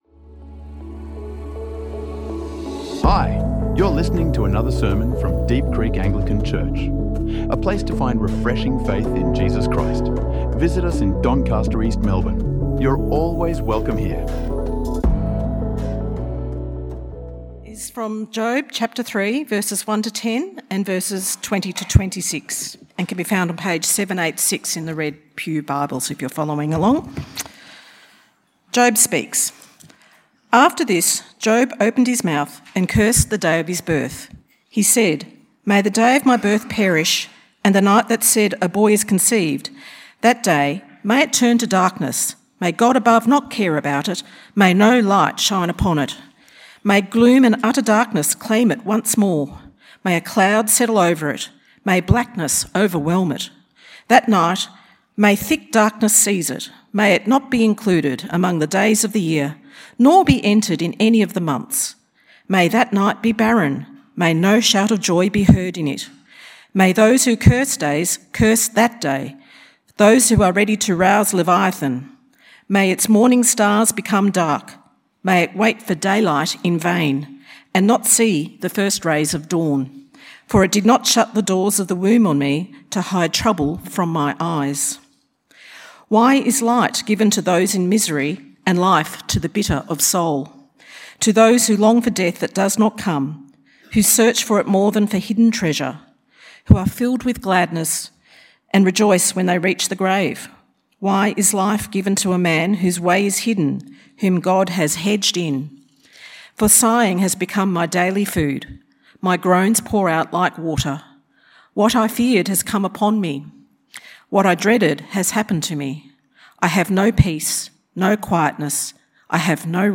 When faith weeps, God listens. Discover hope and honesty in suffering through Job 3:1–26 in this powerful, comforting sermon.